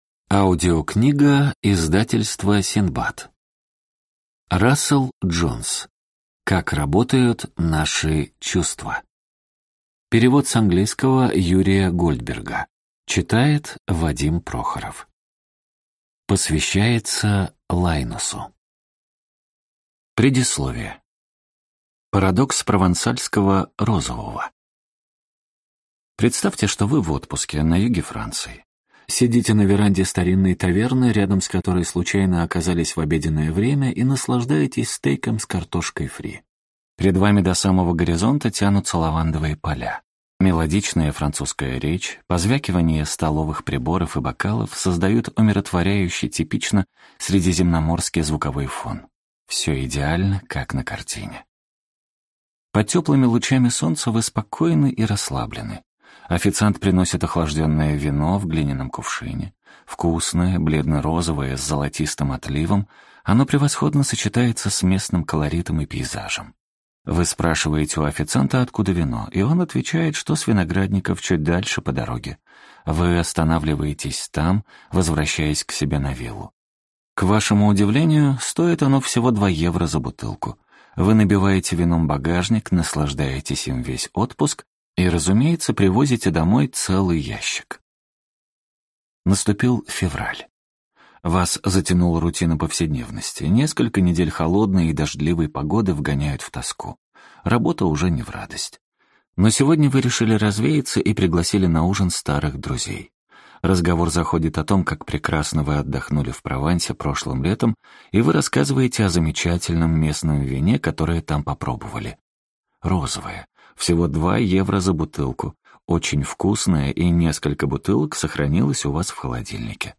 Аудиокнига Как работают наши чувства, или Почему кофе вкуснее из красной чашки | Библиотека аудиокниг